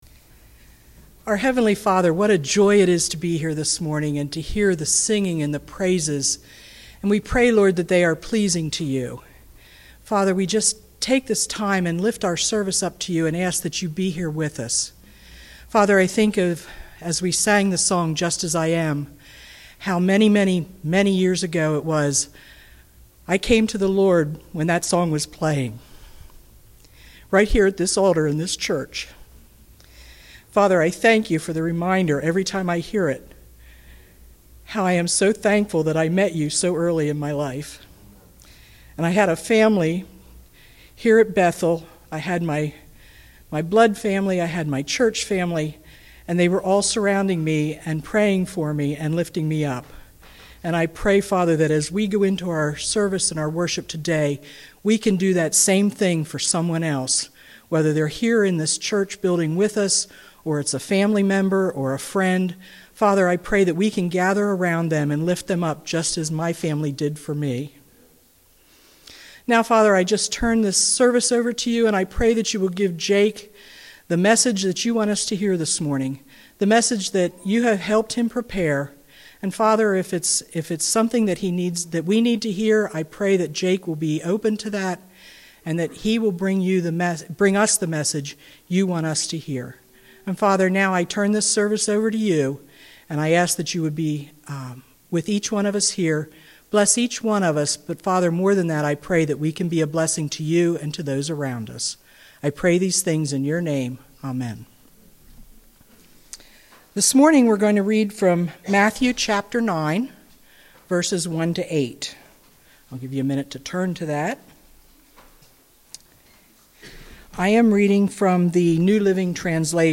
SERMONS » Conestoga Bethel